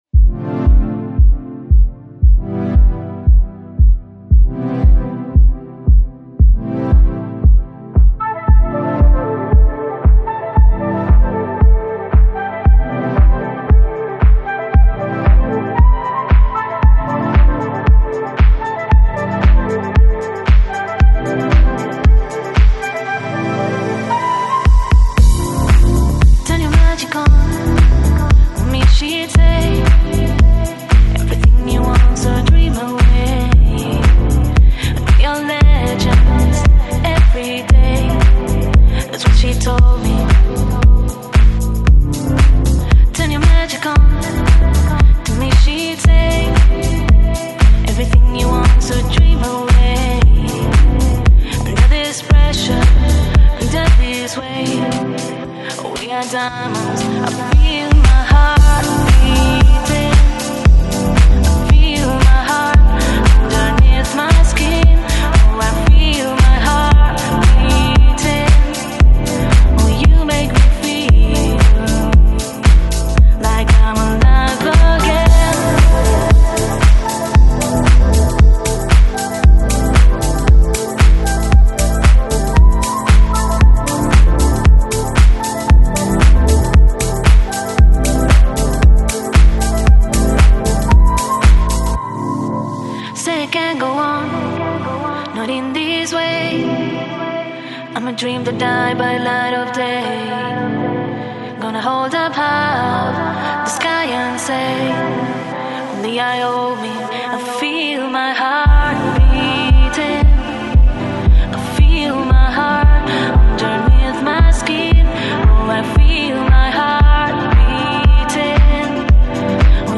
Жанр: Chillout, Lounge, Bossa Nova, Pop